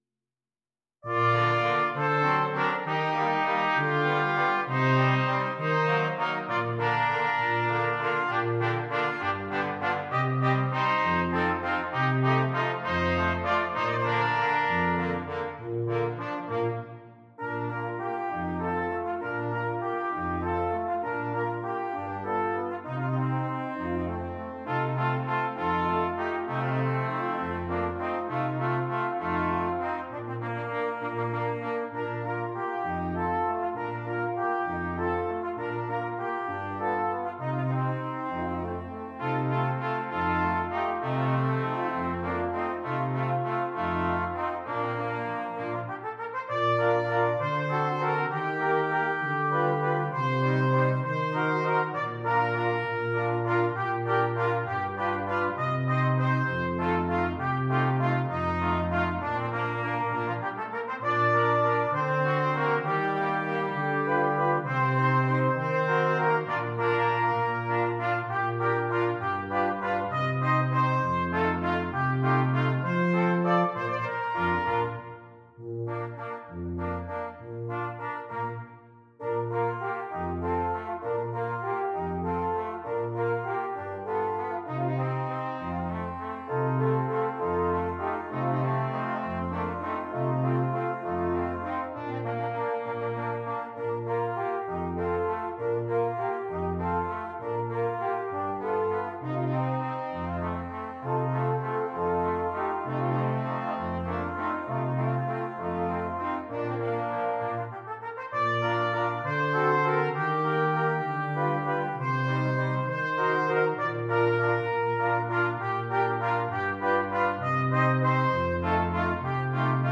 Brass Quintet
Traditional Mexican Folk Song